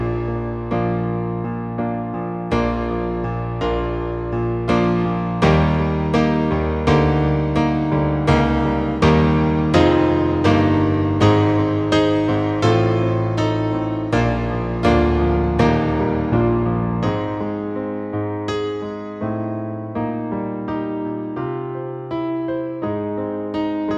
With Harmony Pop (1960s) 3:23 Buy £1.50